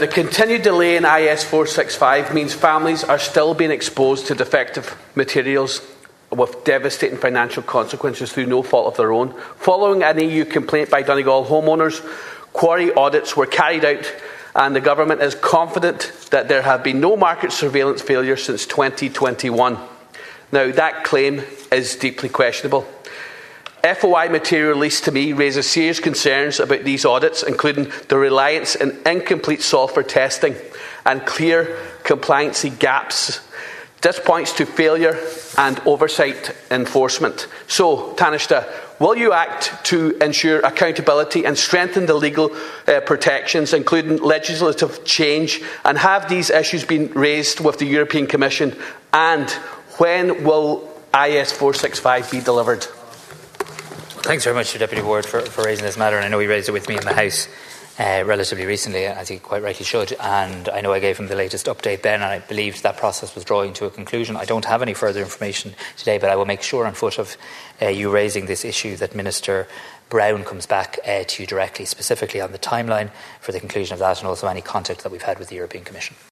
Donegal Deputy Charles Ward has challenged the Government’s record on defective concrete and quarry oversight in the Dáil.